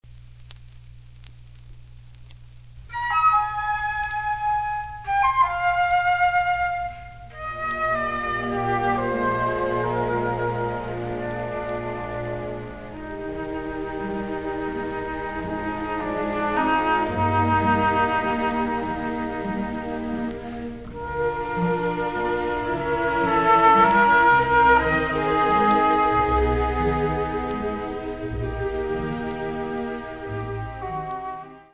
LP Restoration (Before)
LPRESTORATION(BEFORE).mp3